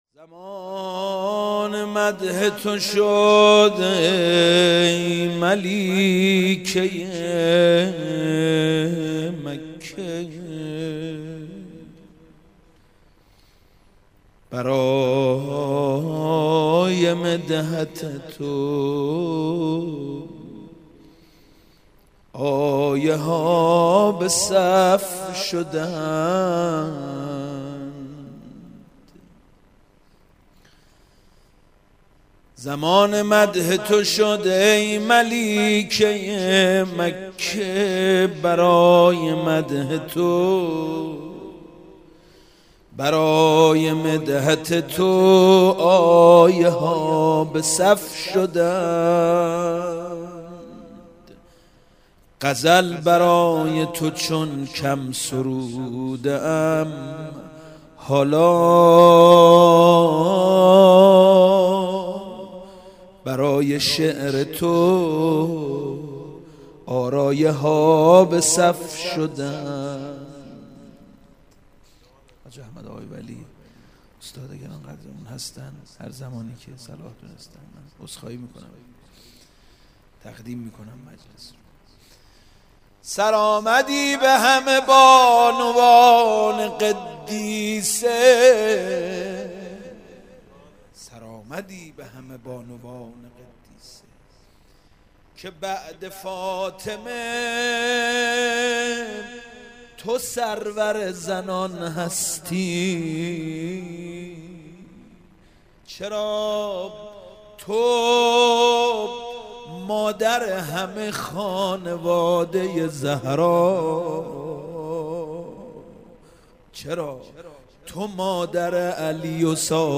در هیات ال یاسین قم برگزار گردید
زمان مدح تو شد ای ملیکه مکه - مدح حضرت خدیجه روضه حضرت زهرا سلام الله علیها لینک کپی شد گزارش خطا پسندها 0 اشتراک گذاری فیسبوک سروش واتس‌اپ لینکدین توییتر تلگرام اشتراک گذاری فیسبوک سروش واتس‌اپ لینکدین توییتر تلگرام